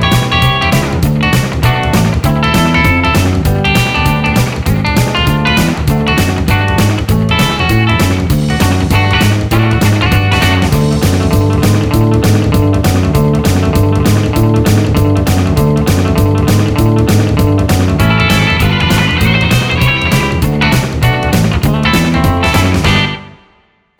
no Backing Vocals Rock 'n' Roll 3:28 Buy £1.50